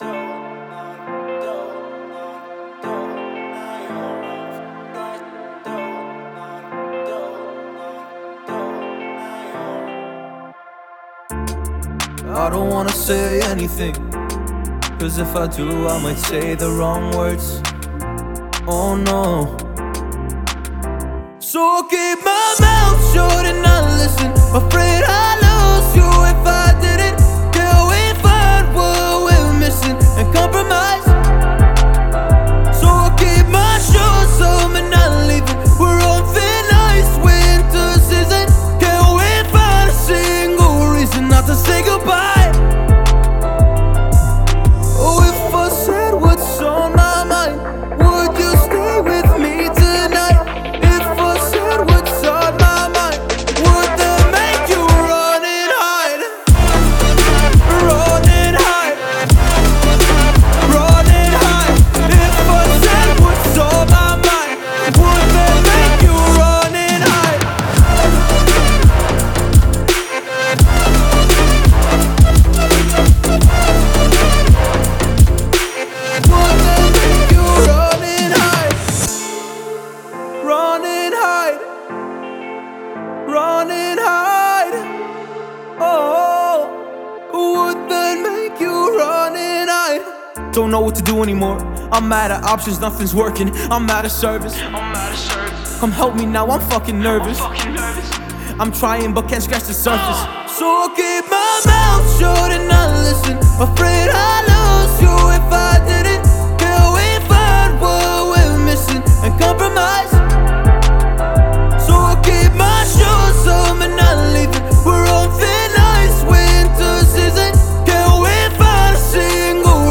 Quirky, Relaxing, Peaceful, Restless